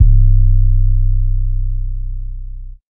808 (Metro 1).wav